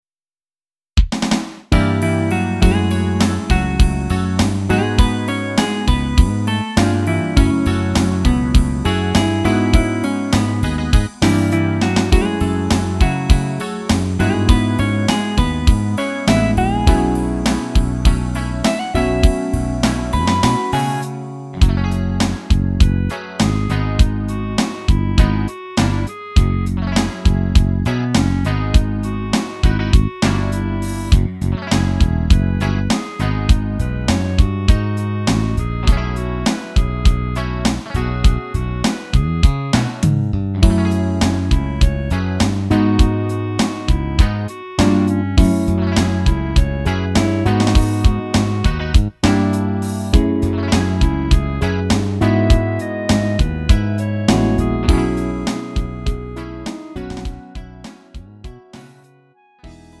음정 원키 3:41
장르 가요 구분